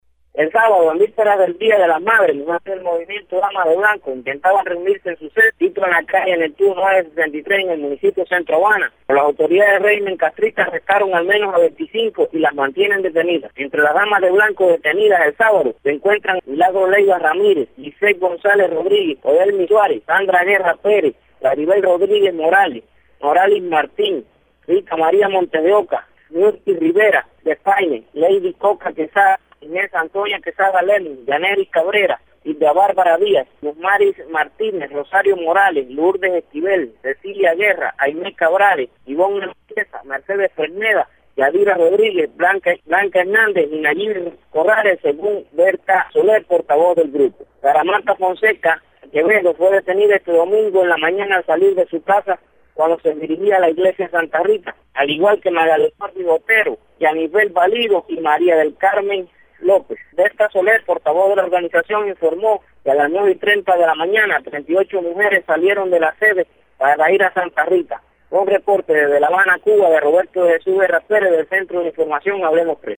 entrevista telefónica con Radio Martí. Los arrestos ocurrieron ayer y hoy en varias partes de la isla.